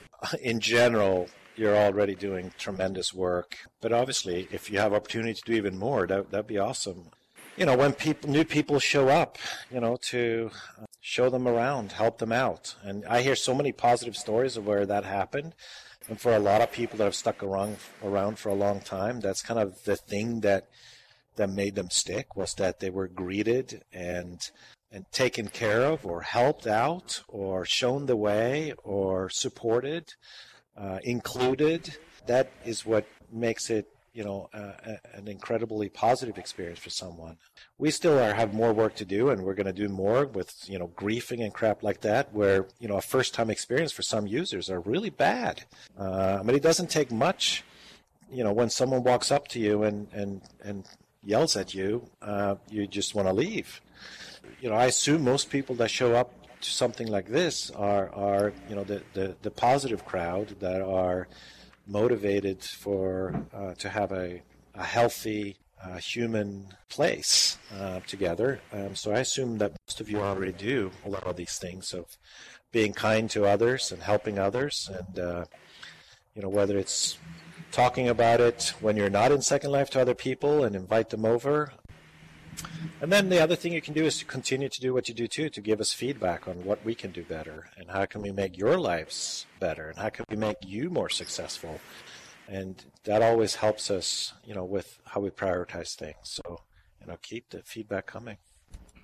answering questions from hosts